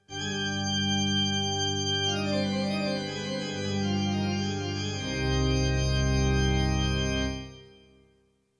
Haunted Organ 1
bonus-sound church-organ film-production game-development halloween haunted intro scary sound effect free sound royalty free Movies & TV